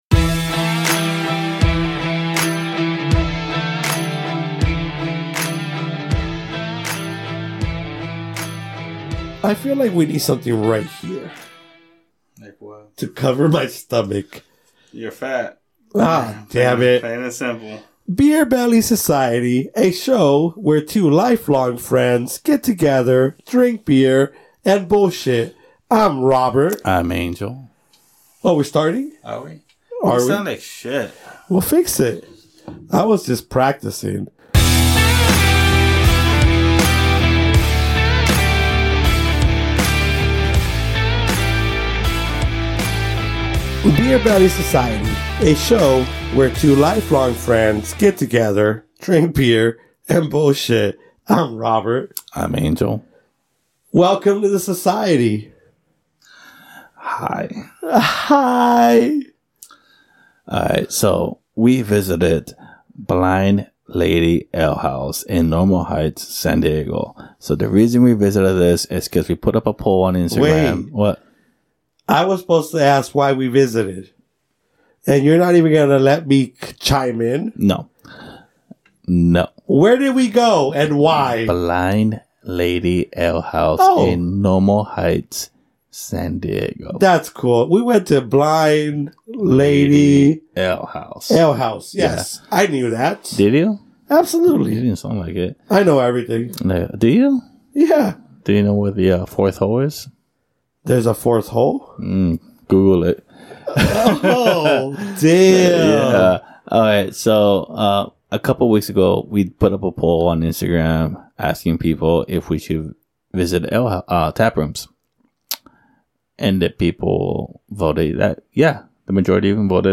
FYI, we were already pretty drunk before we started recording so forgive us lol.